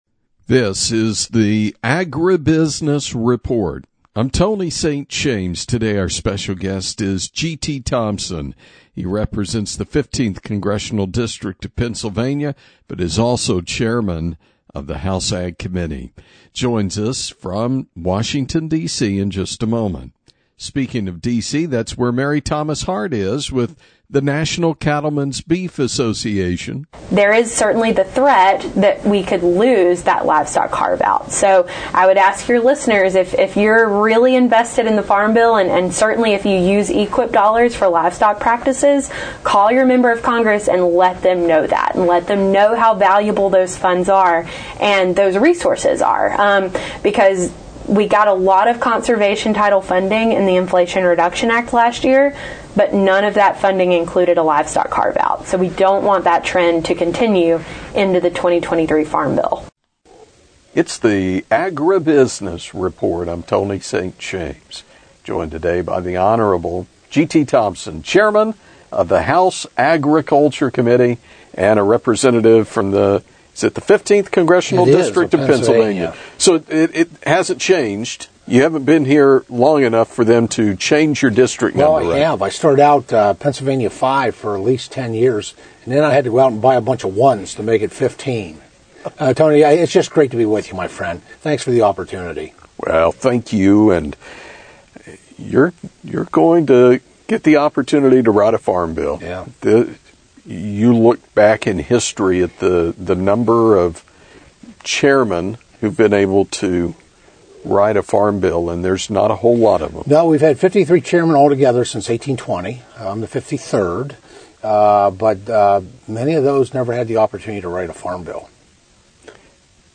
Today’s guest is Rep. G.T. Thompson (PA-15), the Chairman of the House Agriculture Committee, during the 2023 DC Capitol Spring Ag Tour in Washington, DC